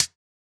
UHH_ElectroHatD_Hit-16.wav